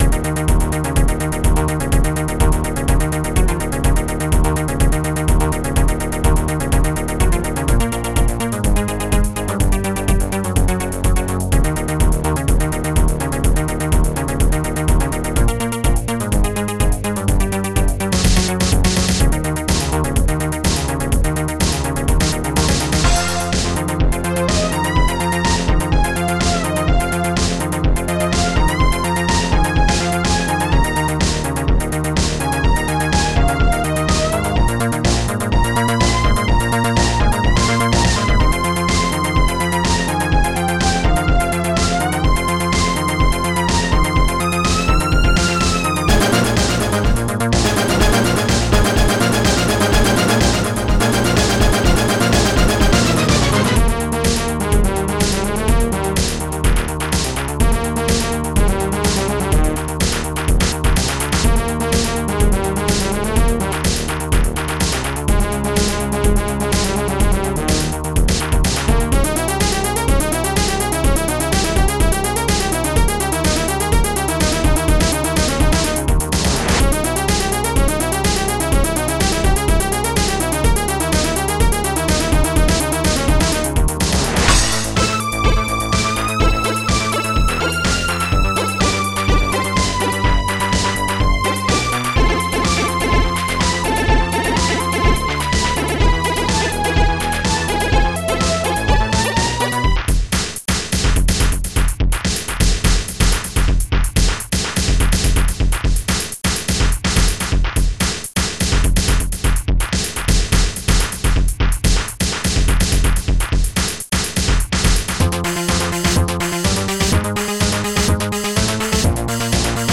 Protracker and family
st-02:bassdrum5
st-03:zzsnare
st-03:crash
st-01:hihat2
st-01:panflute
st-02:fatbrass
st-01:strings3
st-02:accgit
st-99:pullbass